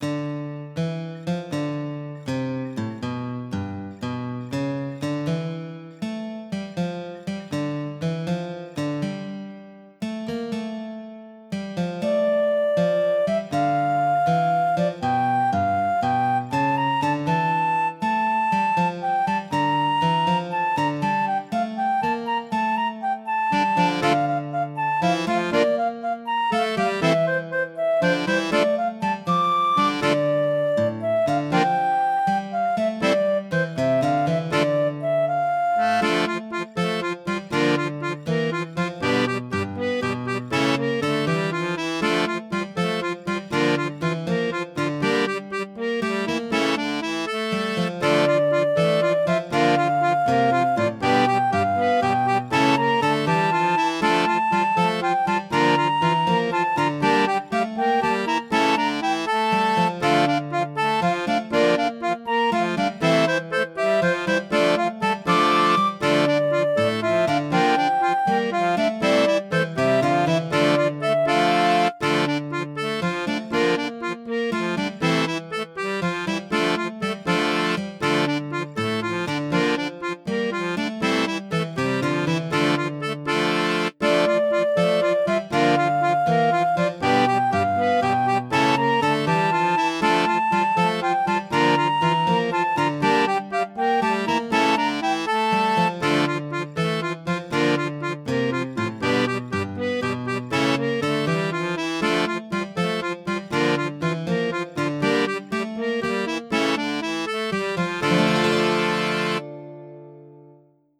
Palavras-chave: Foley
Resumo: Som executado durante a página de tutorial.
Trilha sonora componente do jogo